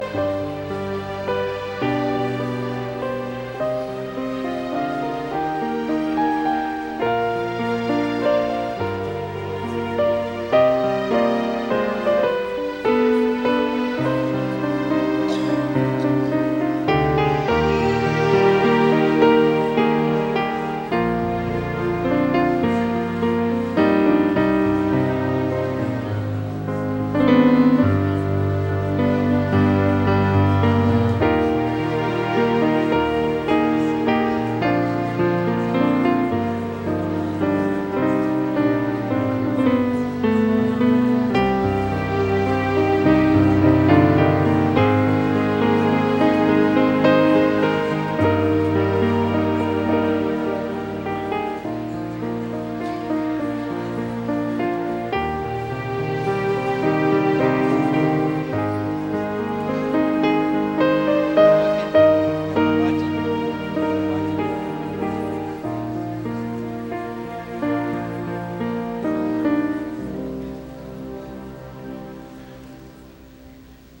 Here is the Music as Choir Entered for the Cantata (looped)
cantata-music-as-choir-entered.mp3